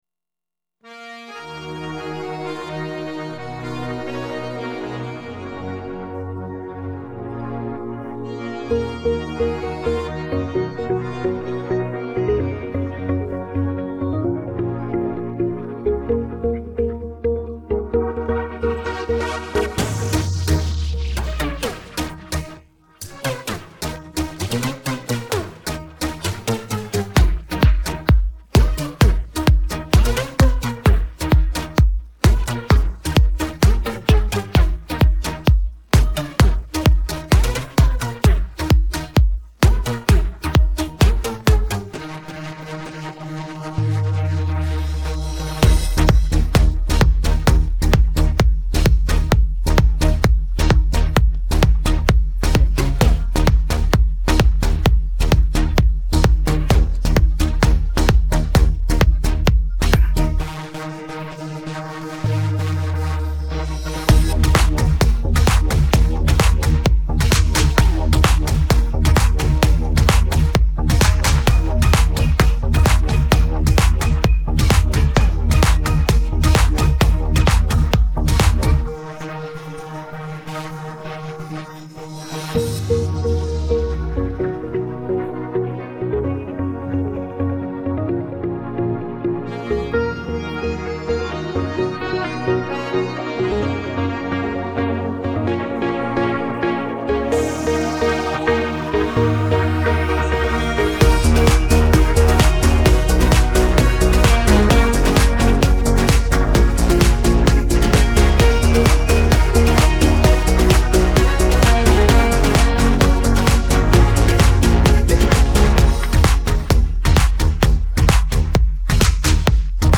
Фоновая музыка